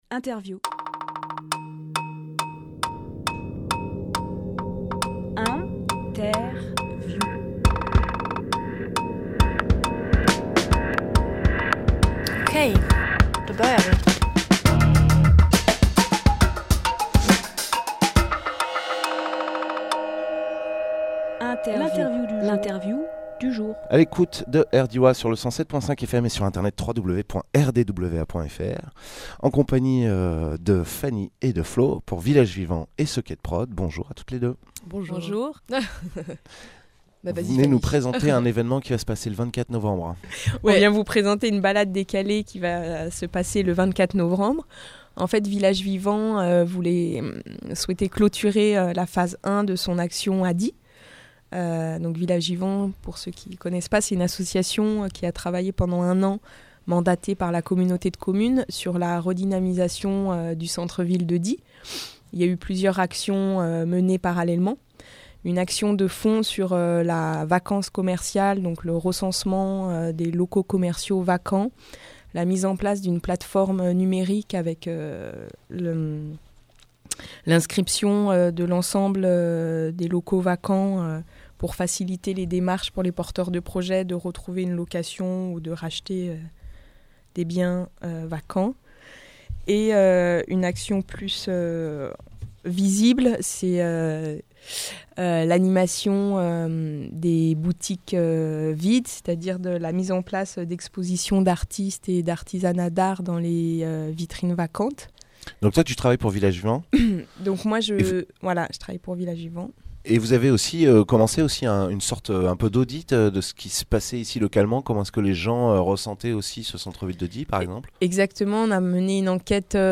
Emission - Interview Villages Vivants & Socket Prod présentent : La Balade Décalée Publié le 20 novembre 2018 Partager sur…
Lieu : Studio RDWA